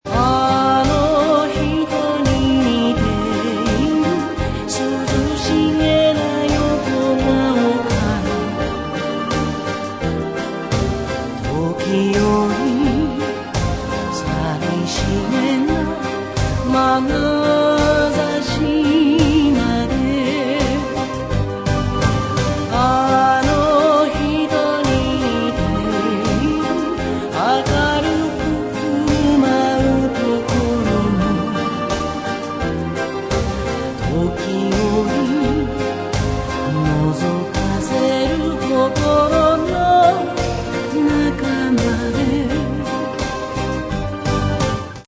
オリジナルアルバム
せつなくあたたかい気持ちがほんのりと胸に蘇る一枚です。